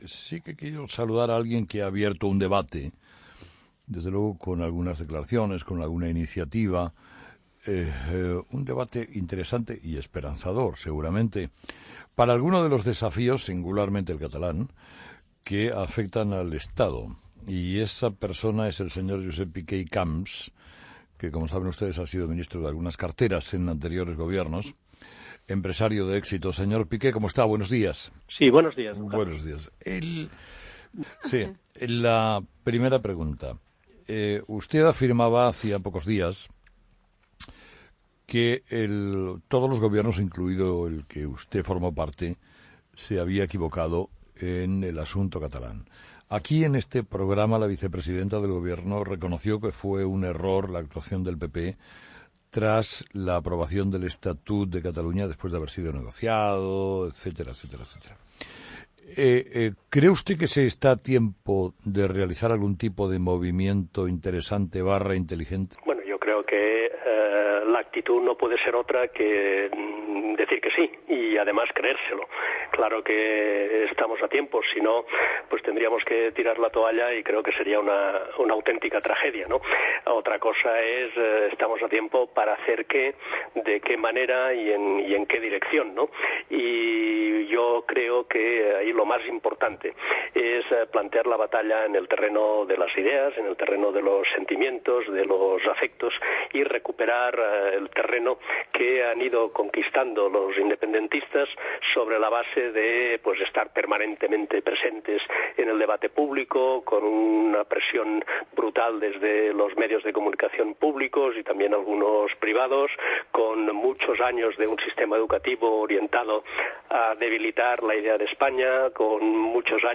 Entrevista con Josep Piqué COPE
Entrevistado: "Josep Piqué"